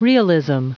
Prononciation du mot realism en anglais (fichier audio)
Prononciation du mot : realism